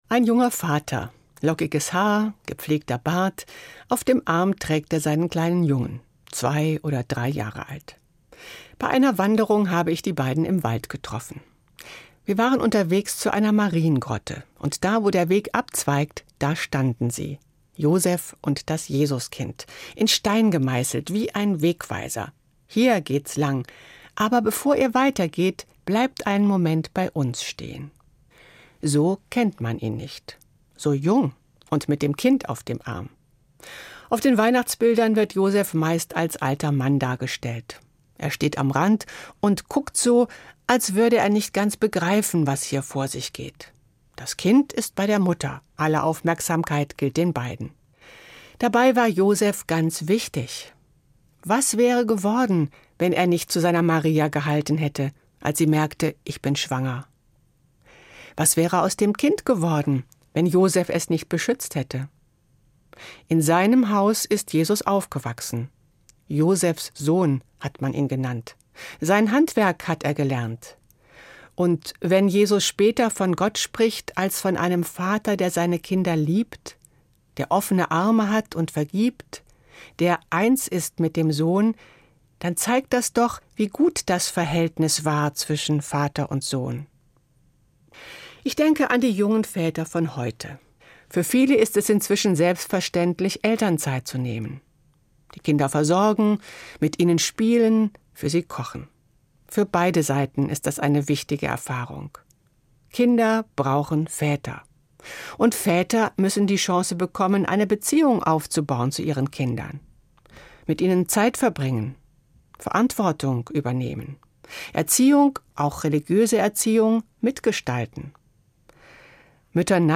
Evangelische Pfarrerin, Marburg